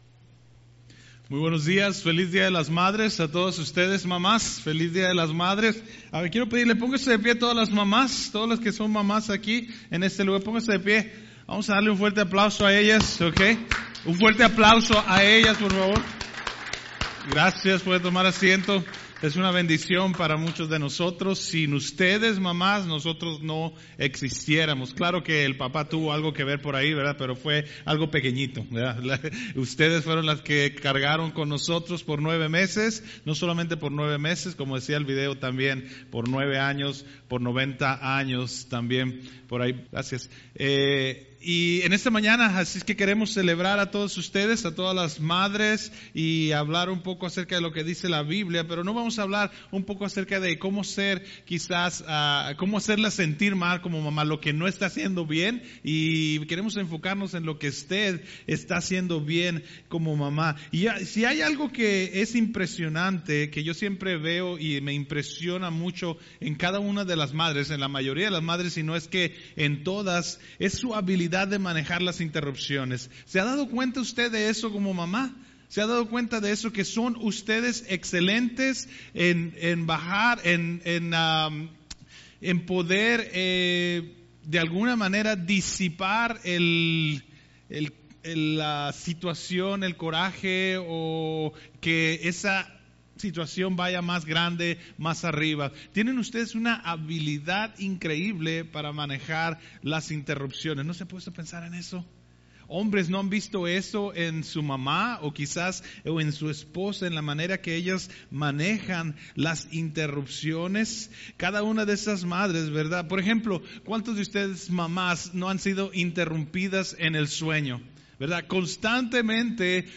La mayoría de las madres enfrenta interrupciones en su día, de hecho todos tenemos interrupciones durante nuestro día, ¿Qué tal interrupciones en nuestra vida?, en este mensaje el Pastor nos enseña que las invitaciones más extraordinarias en nuestra vida vienen envueltas en forma de interrupción.